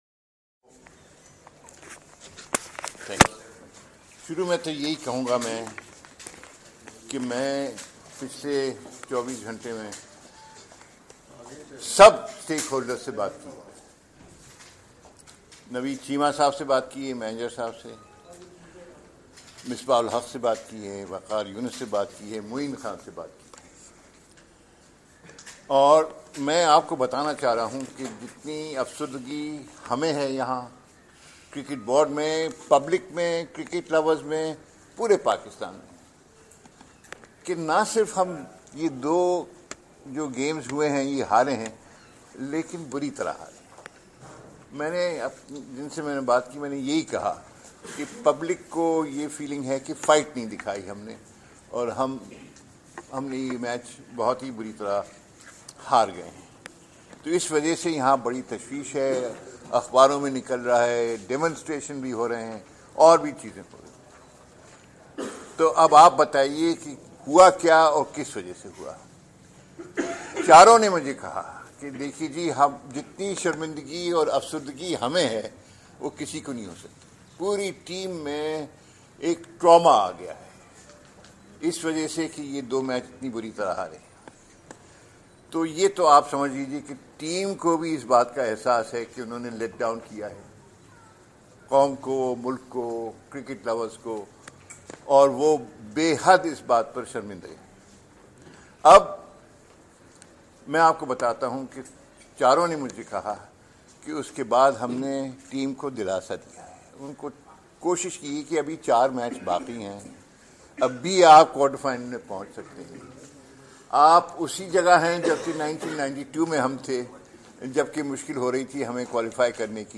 Media Talk: Chairman PCB Mr Shaharyar M. Khan at Gaddafi Stadium, Lahore (Audio)